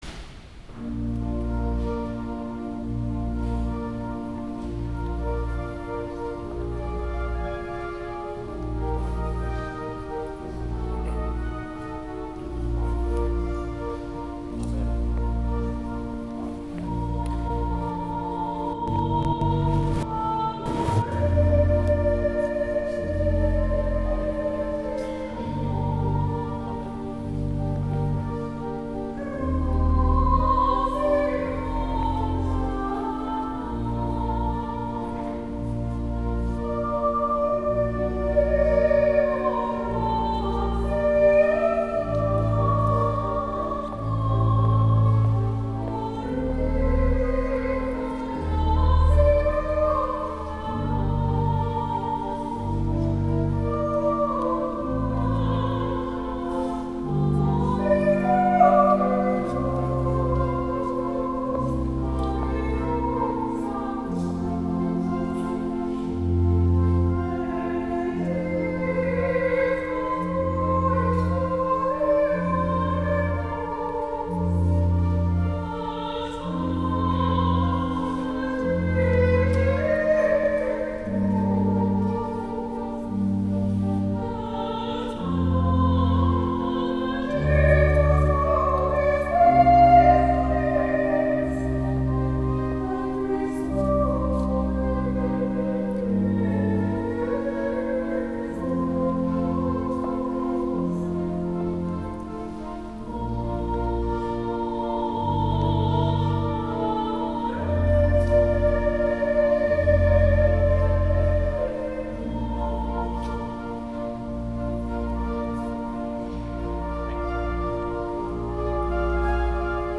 Fourth Sunday in Advent
Holy Eucharist
Cathedral Choir